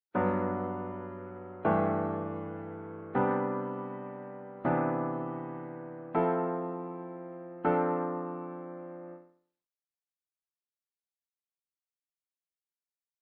Here's a half dozen or so of each chord type, root position, all movable and rooted on 6th, then 5th and 4th strings.
Root position, tonic / One chord type. Example 4.